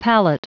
Prononciation du mot palate en anglais (fichier audio)
Prononciation du mot : palate